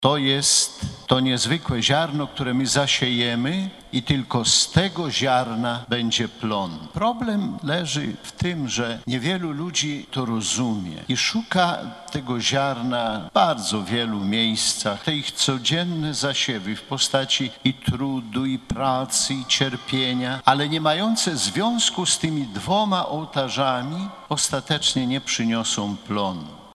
Z tej okazji bp warszawsko-praski przewodniczył uroczystej mszy św. w katedrze św. Michała Archanioła i św. Floriana Męczennika.
W homilii bp Kamiński podkreślił, że owocność ludzkiego życia zależy od głębokiej więzi z Chrystusem. Zachęcał więc członków ruchu do wsłuchiwania się w Boże Słowo i czerpania ze stołu Eucharystii.